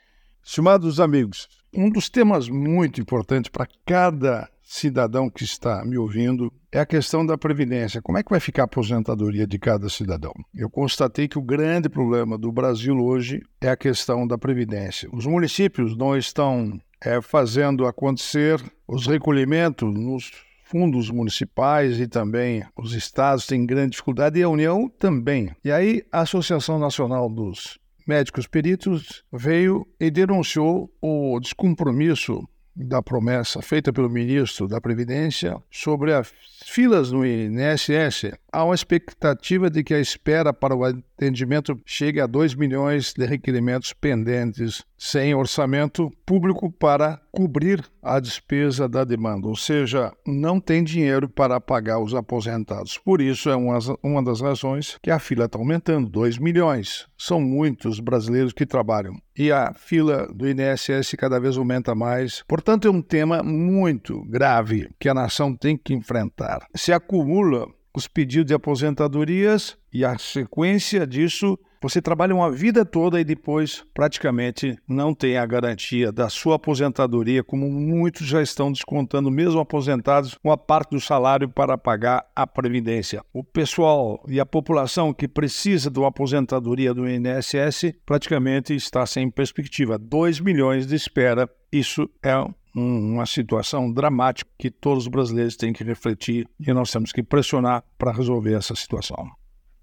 Comentário desta segunda-feira (14/10/24) do ministro do TCU Augusto Nardes.